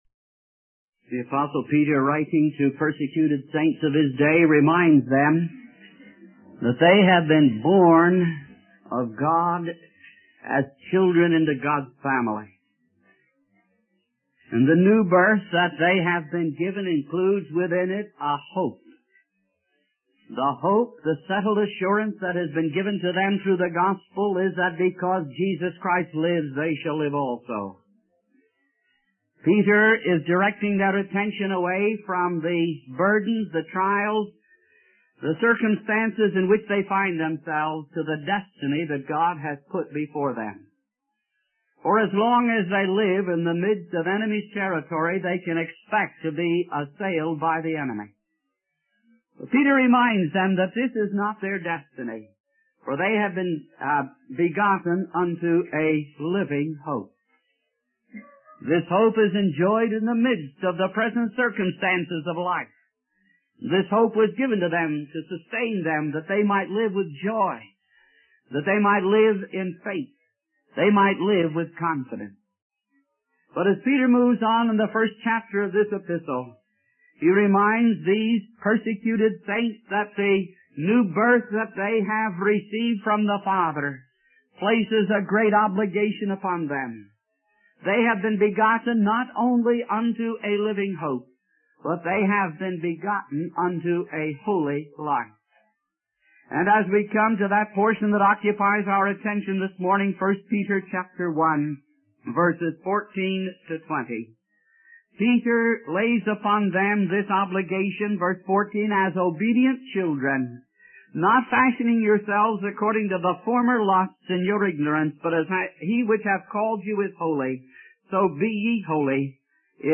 In this sermon, the preacher emphasizes the importance of believers living a holy life, as God is holy.